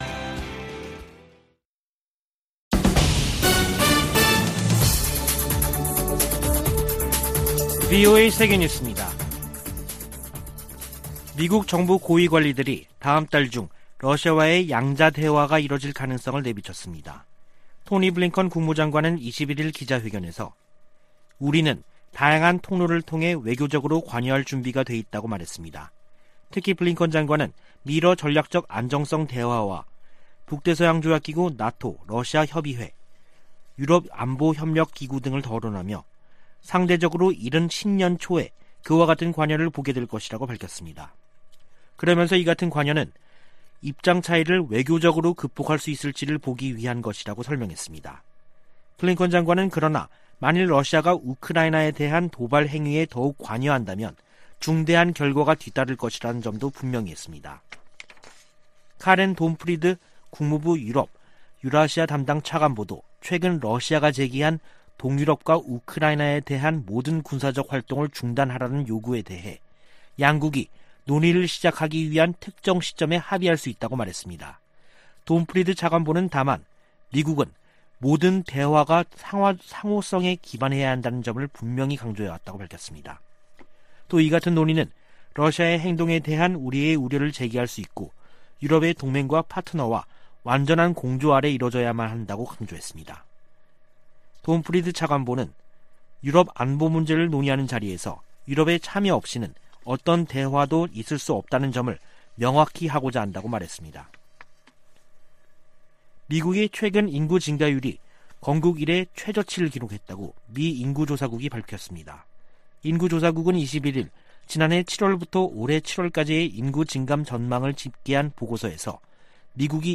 VOA 한국어 간판 뉴스 프로그램 '뉴스 투데이', 2021년 12월 22일 3부 방송입니다. 조 바이든 미국 대통령은 2022년 회계연도에도 북한과 쿠바 등에 인도적 목적 이외 비무역 관련 지원을 하지 않을 것이라고 밝혔습니다. 토니 블링컨 미 국무장관은 인도태평양 지역에 정책과 자원을 집중하고 있다고 밝혔습니다. 미국과 한국은 한국의 증대된 역량과 자율성에 대한 열망을 감안해 동맹을 조정하고 있다고 미 의회조사국이 분석했습니다.